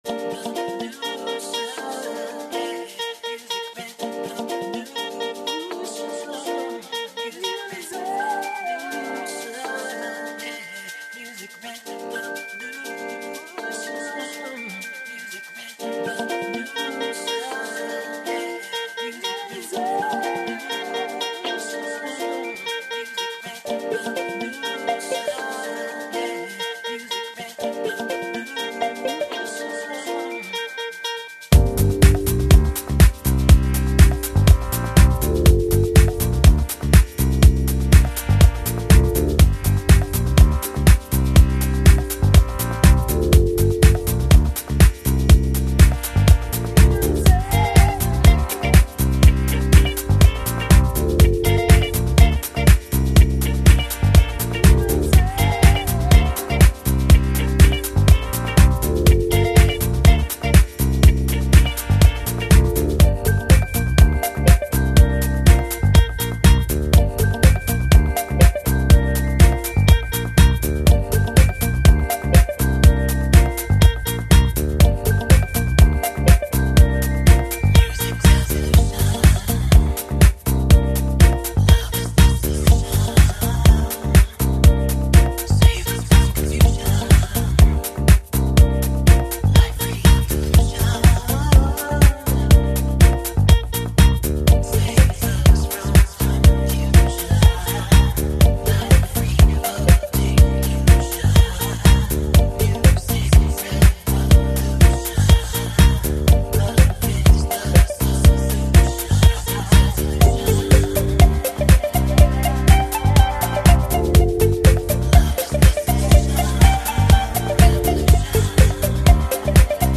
A sophisticated, retro-disco, urban-dance vibe
It’s a retro-disco, floor thumping experience